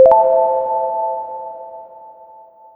player_join.wav